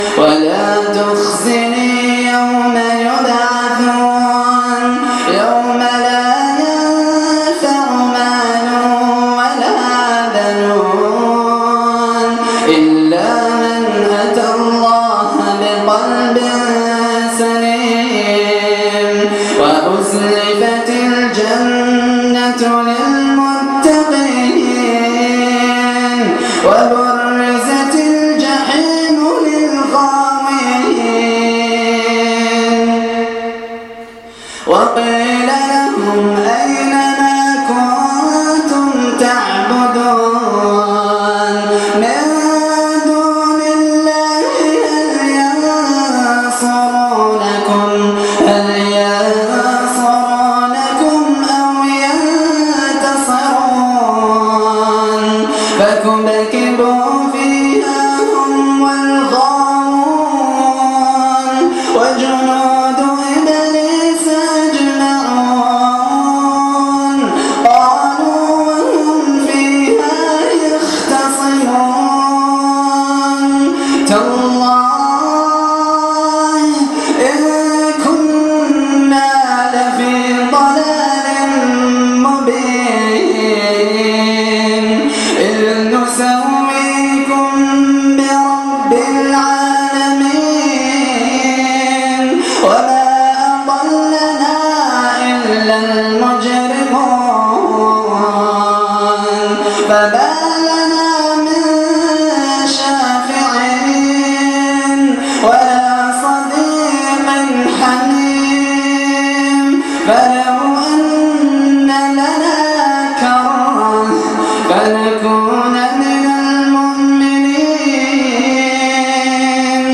من صلاة التراويح